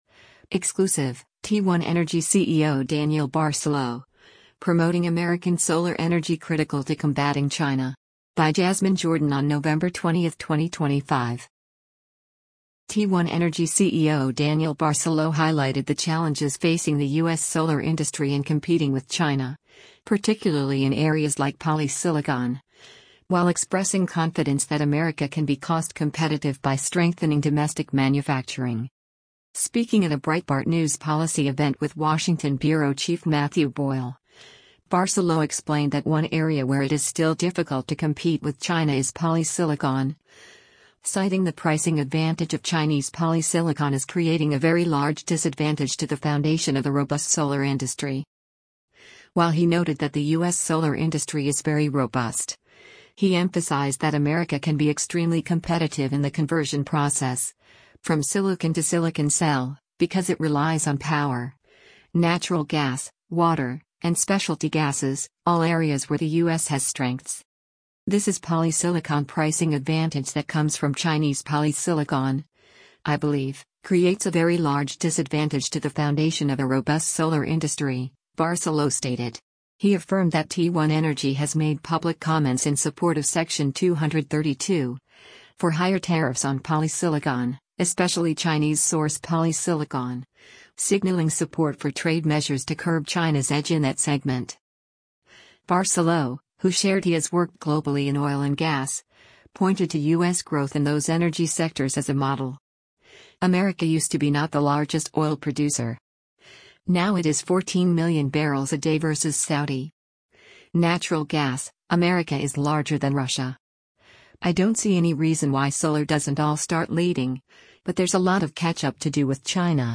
Speaking at a Breitbart News policy event